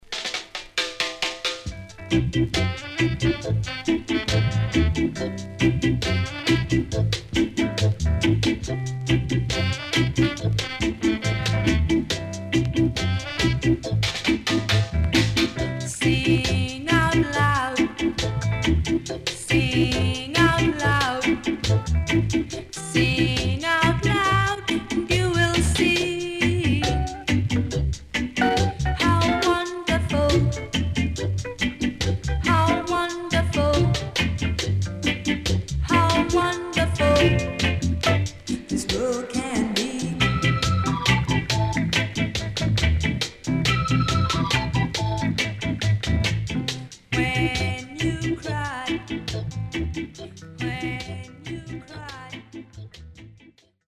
EARLY REGGAE
SIDE A:軽いヒスノイズ入りますが良好です。